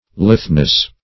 Litheness \Lithe"ness\, n.